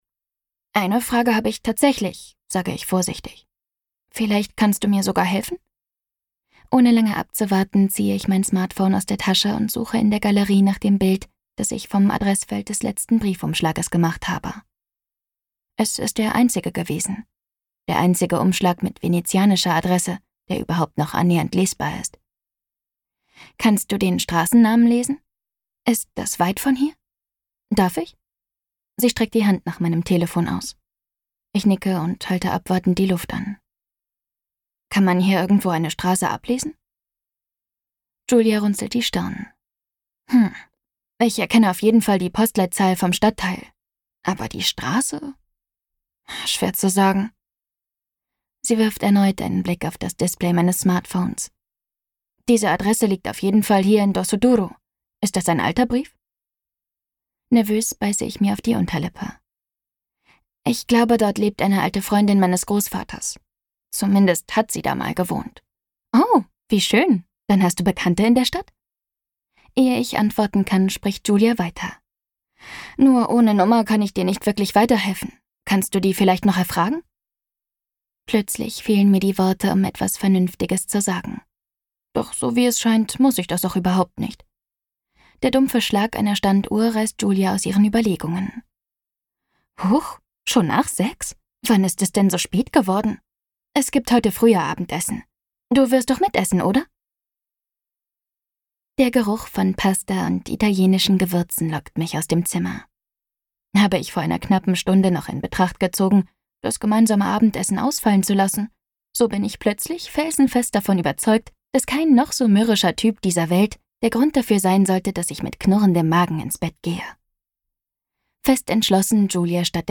Hörbuch E-Book Print